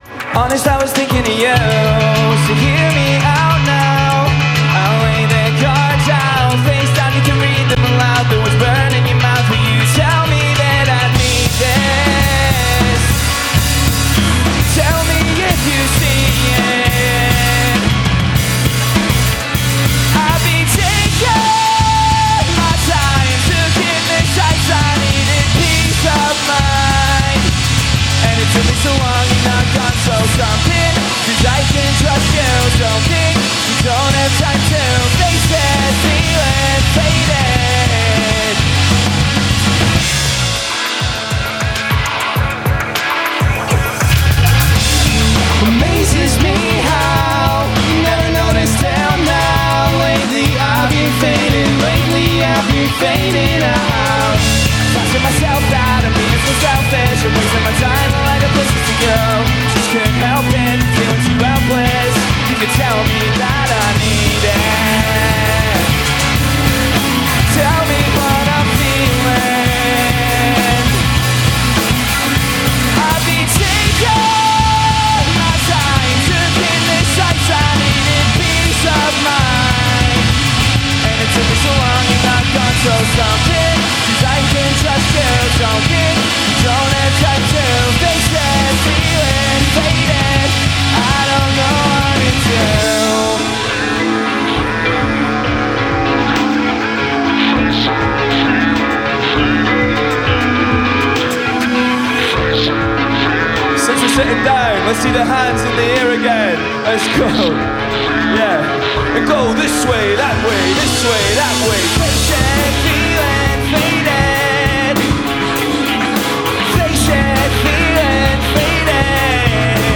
recorded at the Edinburgh Festival this past August